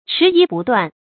迟疑不断 chí yí bù duàn
迟疑不断发音